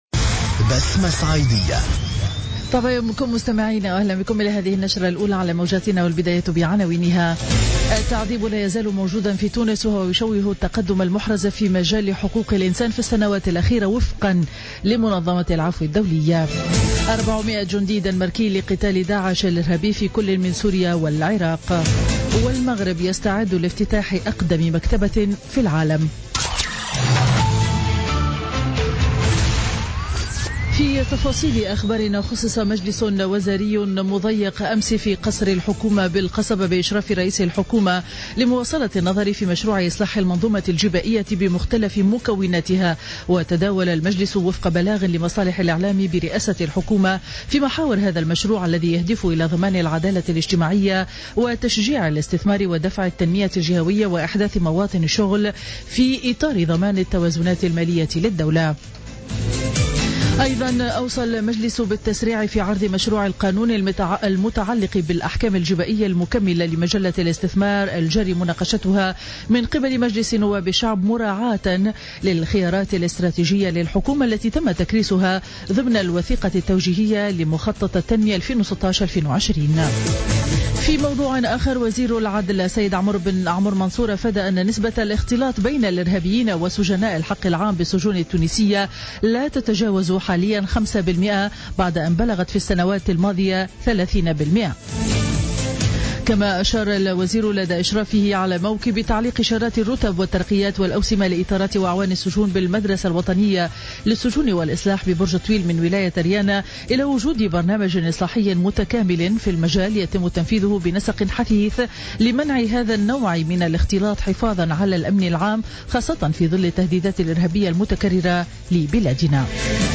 نشرة أخبار السابعة صباحا ليوم الأربعاء 20 أفريل 2016